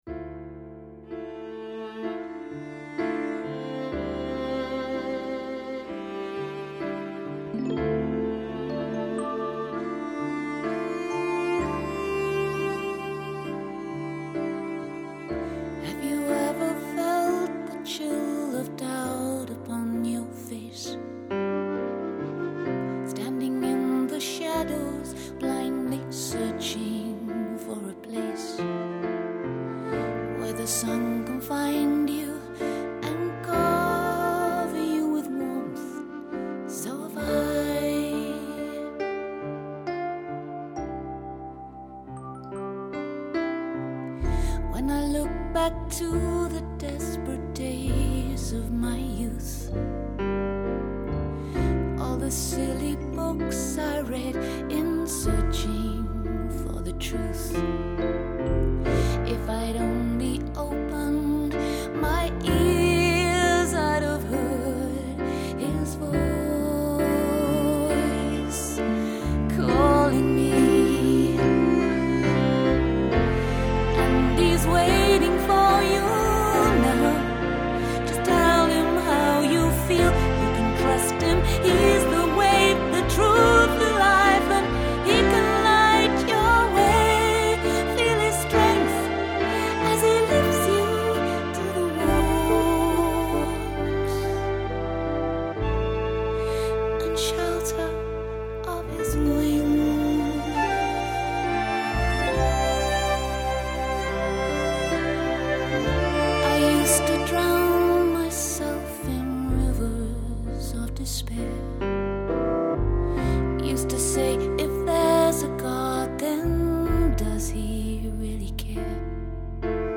Set to music.